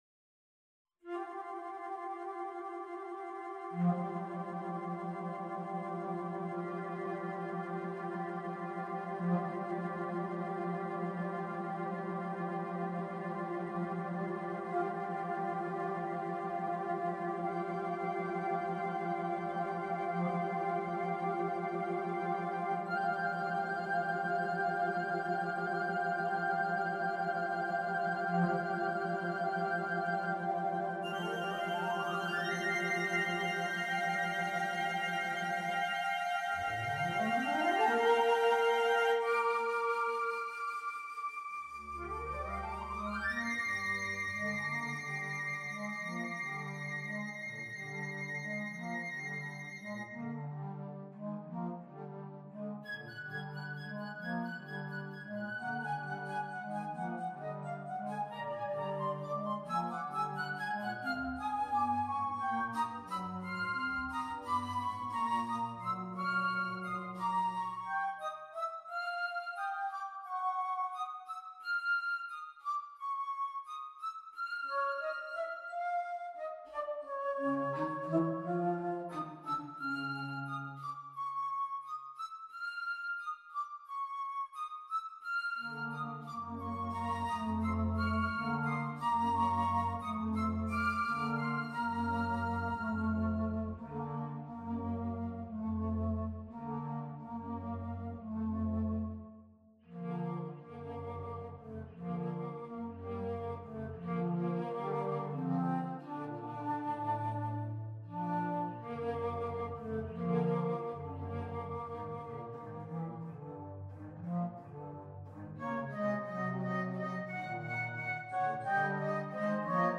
🎵 Download Audio Recording (Final flute quartet performance)
• C Flute - The lead voice - sometimes neuklyuzhiy
• Alto Flute - The warm middle register
• Bass Flute - The brooding lower voice and surprisingly sometimes the lead voice - surprising even the bass flutist themself
• Contrabass Flute - The impossible anchor